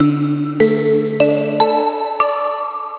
Операторите са задължени да уведомяват потребителите чрез звуков сигнал (чуйте го
Сигналът е с продължителност 3 секунди, а освен това имате още 2 секунди, в които да решите дали да прекратите обаждането или да продължите с него.